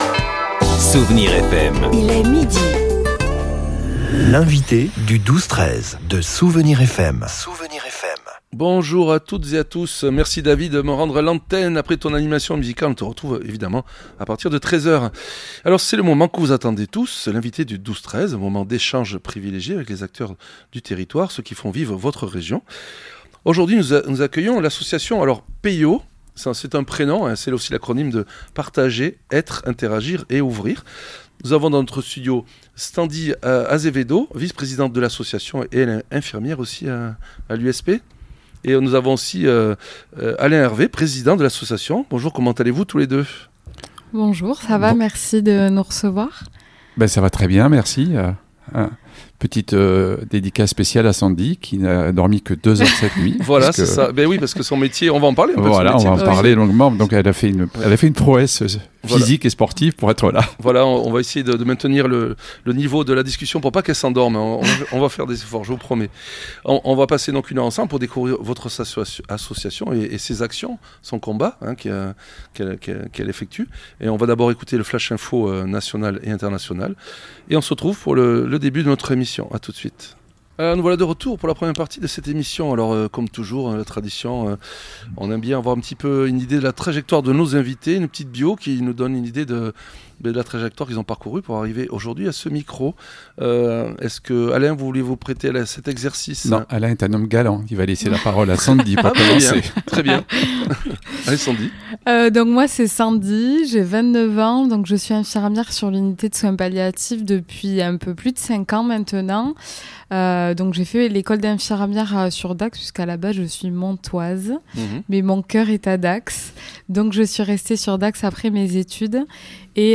Une conversation profondément touchante s'est tenue dans notre studio avec